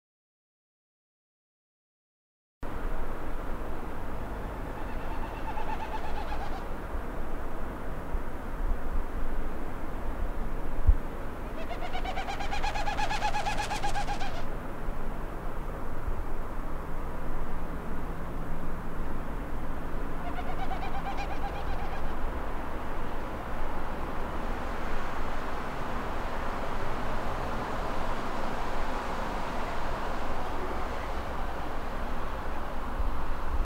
Ehdimme hädin tuskin nousta autosta, kun taivaalta jo kuului helmipöllömäistä puputusta ja löysimme äänen aiheuttajan lentelemästä taivaalta laajaa kaarrosta ja syöksyen aika ajoin pyrstösulat puputtaen viistosti alaspäin.
Äänittäminen oli mahdotonta ennen kuin lähes kaikki bongarit olivat lähteneet ja lopulta sain jonkinlaiset äänitykset linnusta maassa ja vielä puolisen tuntia sormet kohmeessa odoteltuani lintu nousi taas taivaalle soimaan ja sain myös puputuksen äänitettyä!